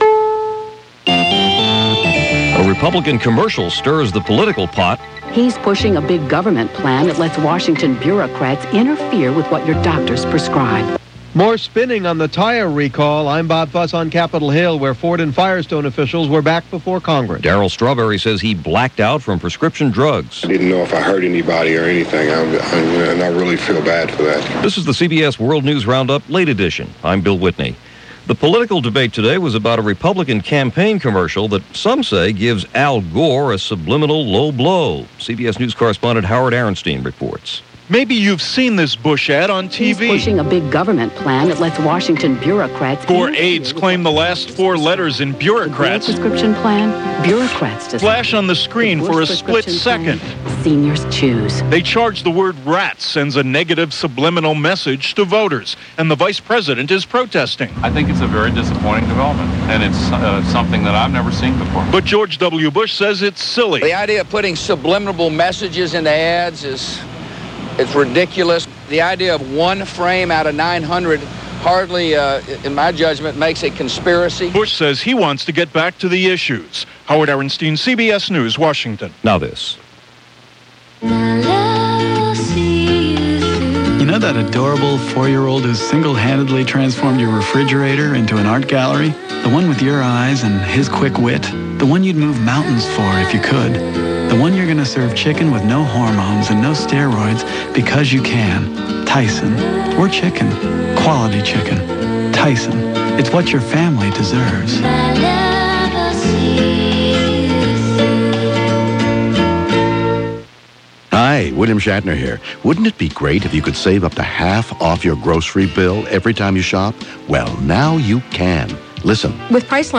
And that’s just a small sample of what went on, this September 12, 2000 as reported by the CBS World News Roundup: Late Edition.